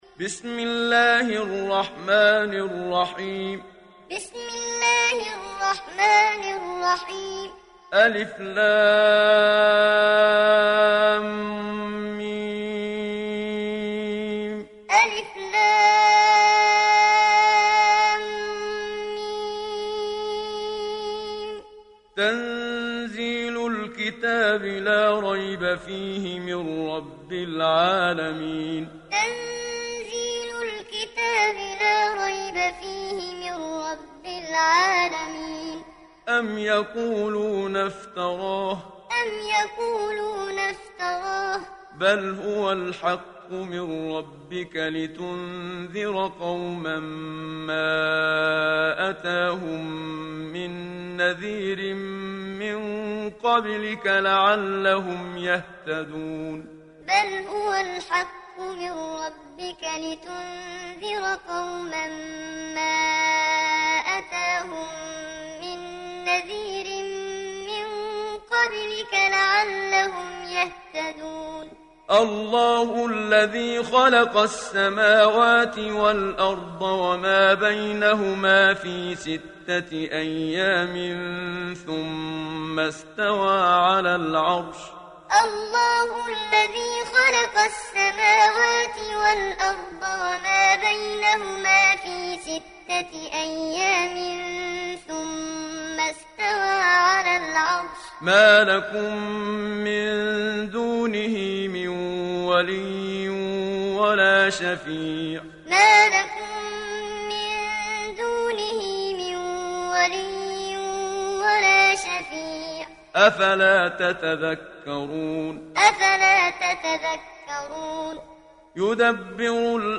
সূরা আস-সাজদা ডাউনলোড mp3 Muhammad Siddiq Minshawi Muallim উপন্যাস Hafs থেকে Asim, ডাউনলোড করুন এবং কুরআন শুনুন mp3 সম্পূর্ণ সরাসরি লিঙ্ক